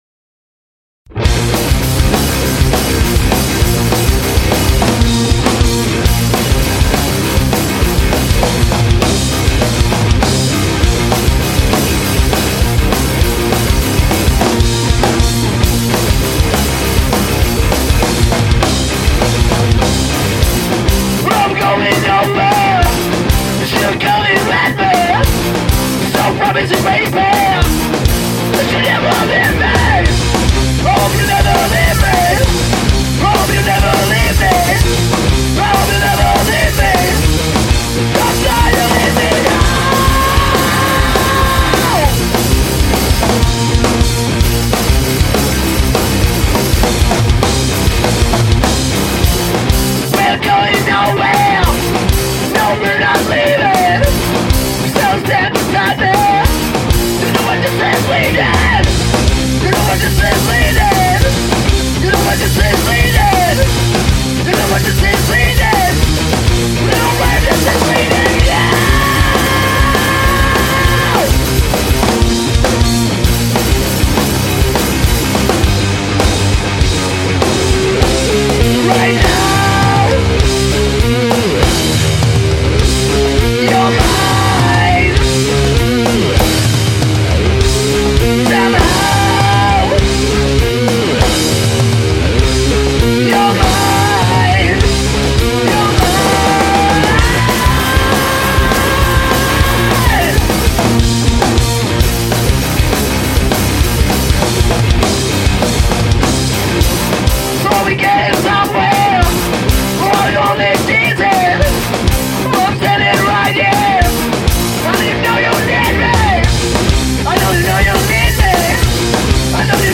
Žánr: Rock
Guitar, Vox
Drums, Vox